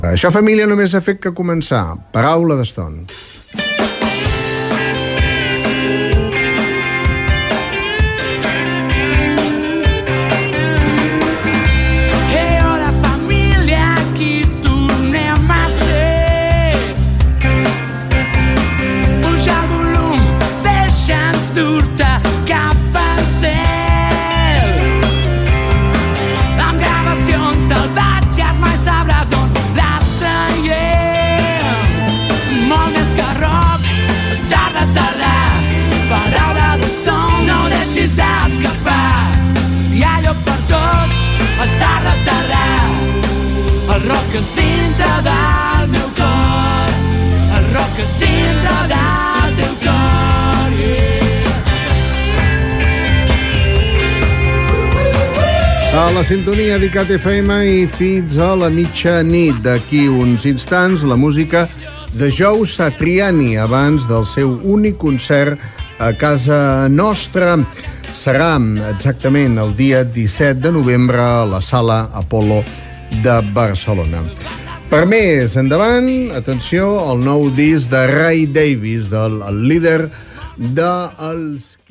Identificació i cançó del programa. Identificació de l'emissora, anunci d'un concert a Barcelona.
Musical